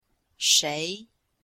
shei2-f.mp3